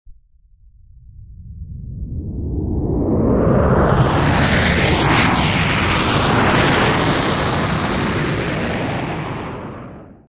Download Free Airplane Sound Effects
Airplane